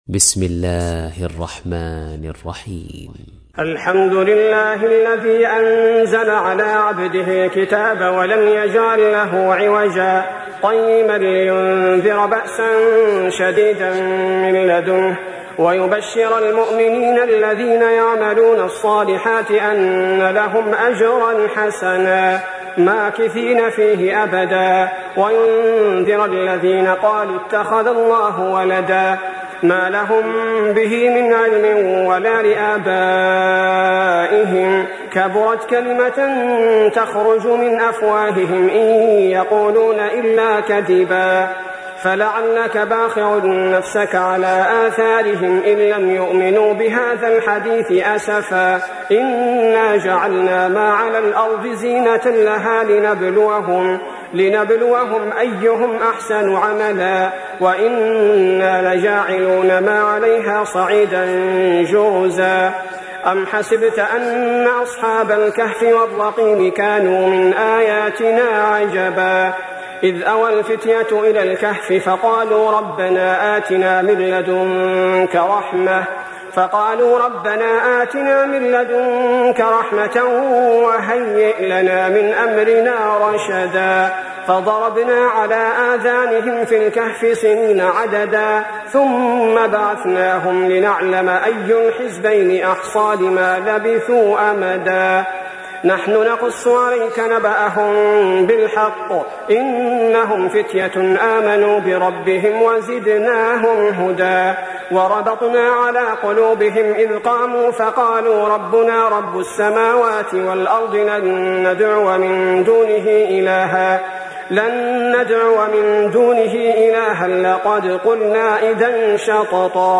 تحميل : 18. سورة الكهف / القارئ صلاح البدير / القرآن الكريم / موقع يا حسين